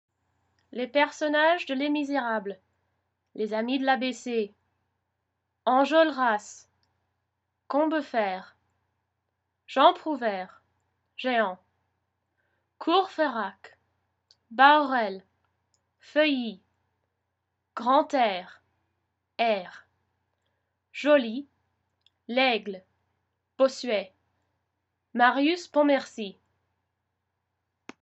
My pronunciations of the names of the Amis de l’ABC.
Source: Native French speaking self. You can blame Victor Hugo and the French language for all the French r’s.